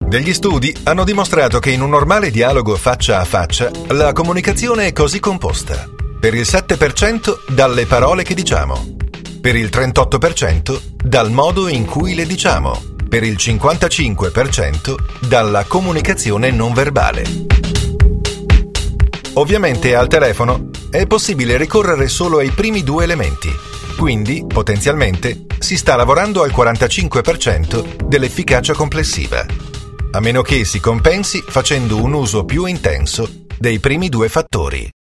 Speaker italiano madrelingua, voce calda e professionale, rassicurante e convincente.
Sprechprobe: eLearning (Muttersprache):
My voice is deep and warm, reassuring and convincing, ideal for narrations, commercials, smooth and professional for documentaries, multimedia project dvds, and many more.